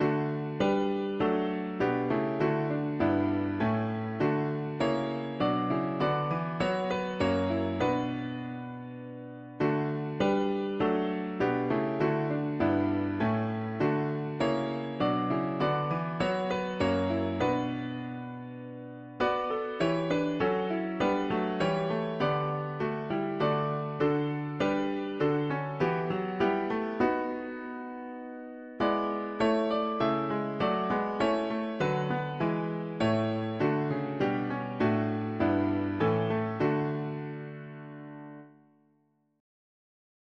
O sacred Head, what glory, wha… english christian 4part death
Key: D major Meter: 76.76 D